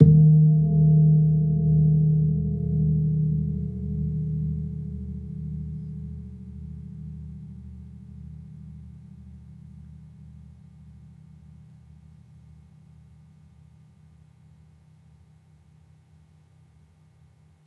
healing-soundscapes/Gong-D#2-f.wav at b1d1eb802c1add98892e0a11c12ba7b820dea4b7
Gong-D#2-f.wav